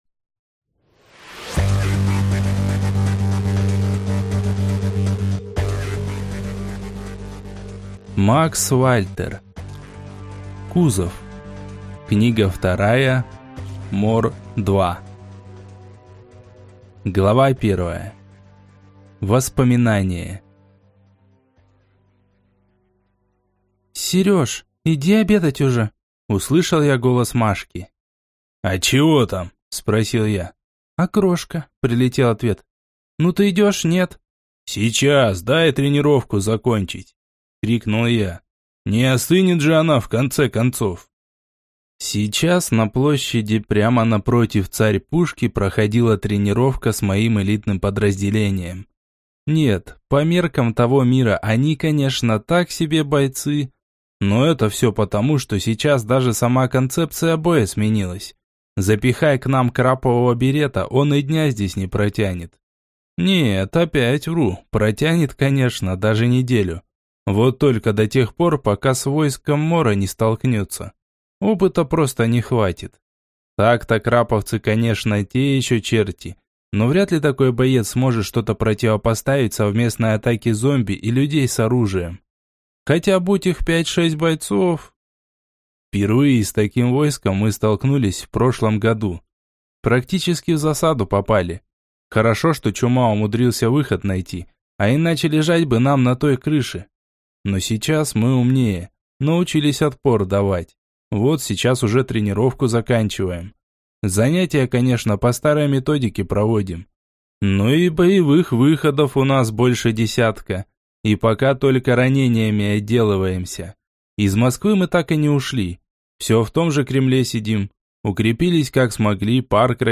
Аудиокнига МОР 2 | Библиотека аудиокниг